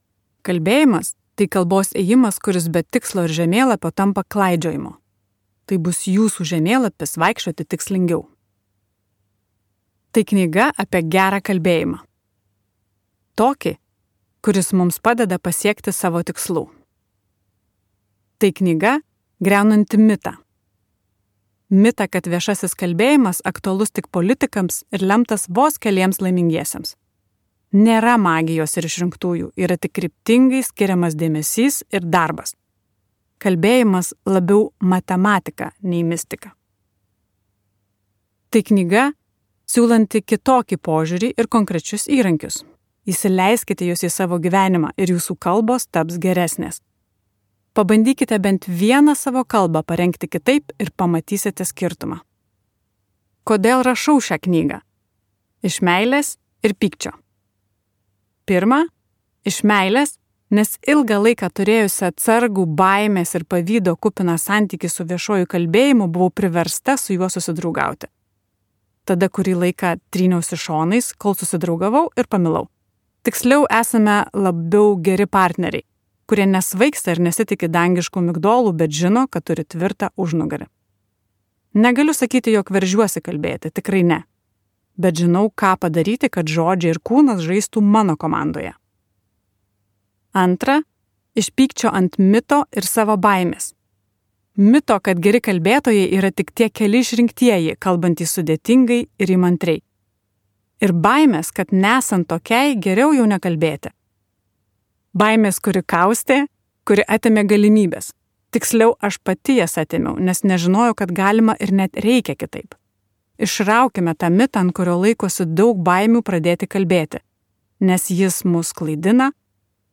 Gerai kalbėti gali visi | Audioknygos | baltos lankos